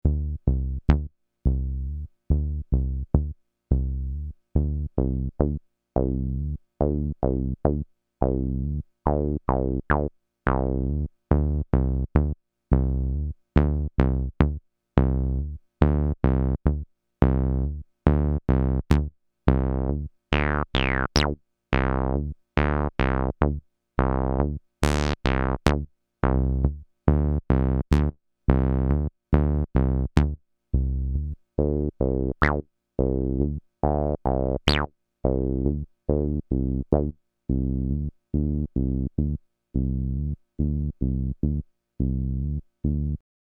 Cyclone TT-303 Bassbot
Basic 16 step 5 note pattern with slide and accent
Pattern sweeps through the filter, resonance, envelope, and decay
No EQ, No compression
The results above are raw audio, without eq, compression, or treatment.